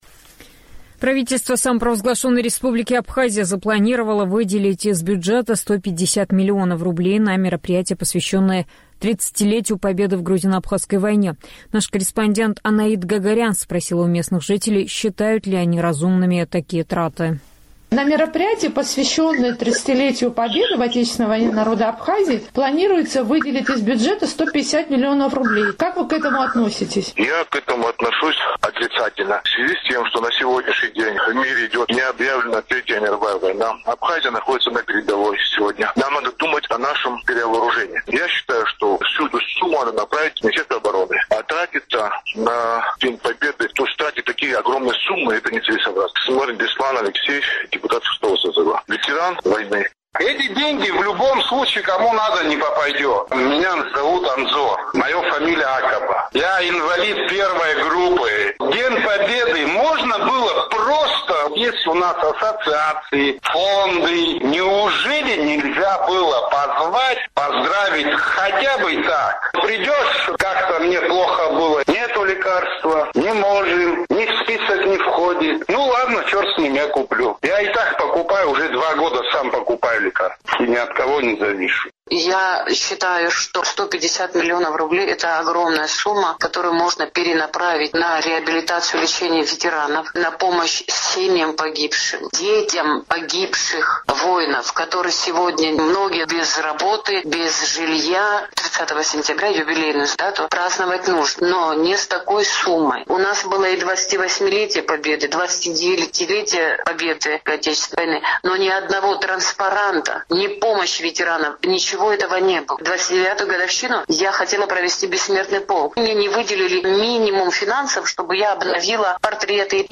Сухумский опрос – о тратах на празднование 30-летия победы в грузино-абхазской войне
Правительство Абхазии запланировало выделить из бюджета 150 миллионов рублей на мероприятия, посвященные тридцатилетию победы в грузино-абхазской войне. «Эхо Кавказа» спросило у местных жителей, считают ли они разумными такие траты.